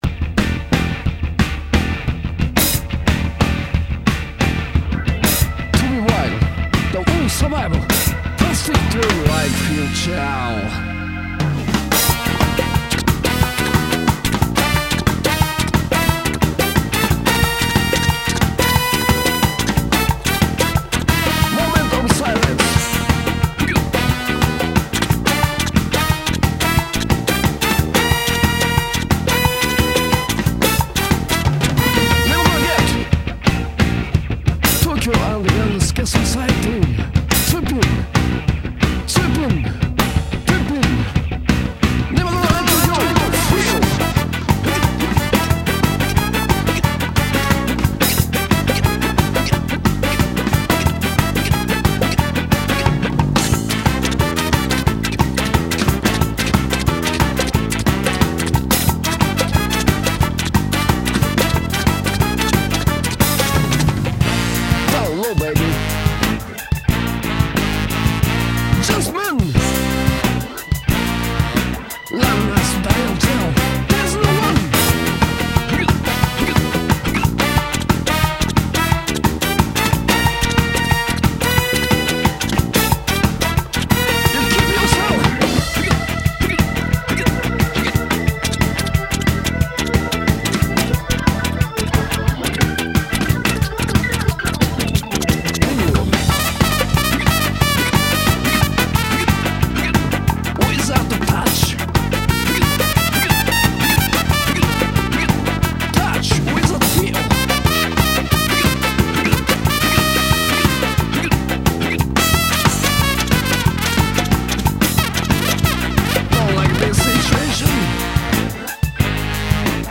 BPM150-183
Audio QualityPerfect (High Quality)